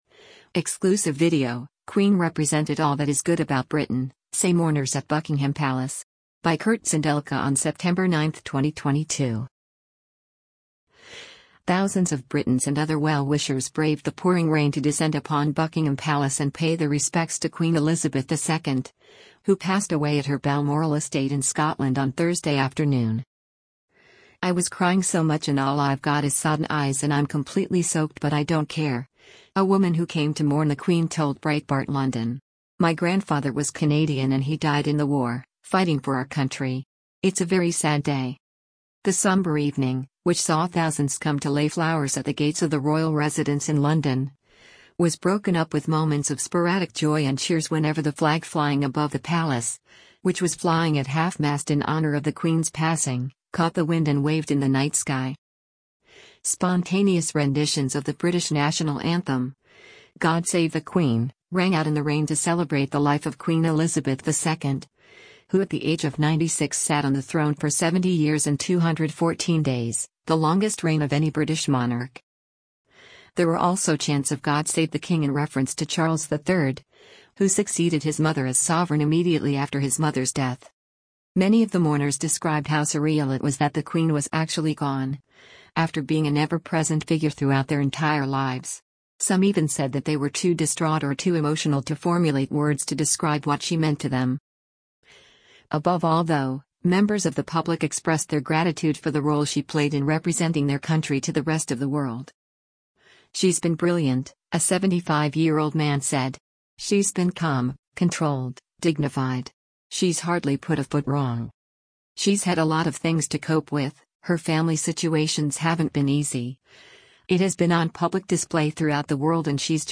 Exclusive Video: Queen Represented ‘All That Is Good About Britain’, Say Mourners at Buckingham Palace
Thousands of Britons and other well-wishers braved the pouring rain to descend upon Buckingham Palace and pay their respects to Queen Elizabeth II, who passed away at her Balmoral estate in Scotland on Thursday afternoon.
The sombre evening, which saw thousands come to lay flowers at the gates of the Royal residence in London, was broken up with moments of sporadic joy and cheers whenever the flag flying above the palace — which was flying at half-mast in honour of the Queen’s passing — caught the wind and waved in the night sky.
Spontaneous renditions of the British national anthem, God Save the Queen, rang out in the rain to celebrate the life of Queen Elizabeth II, who at the age of 96 sat on the throne for 70 years and 214 days — the longest reign of any British monarch.